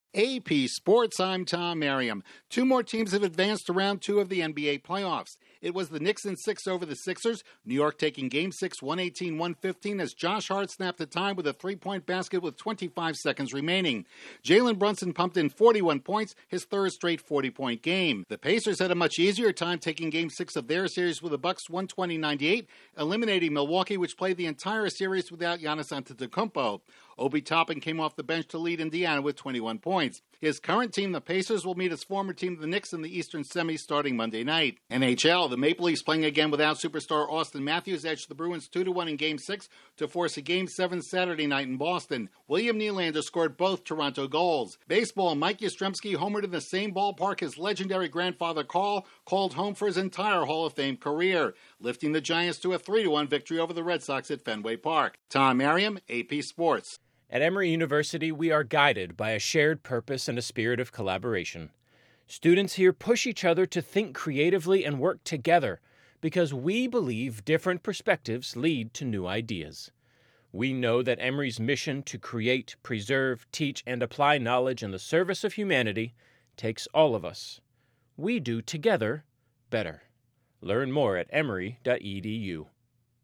Two more teams advance to the second round of the NBA Playoffs, one Stanley Cup playoff series is heading to Game 7, and Mike Yastrzemski homers at Fenway, the ballpark that was the career home to his Hall of Fame grandfather. Correspondent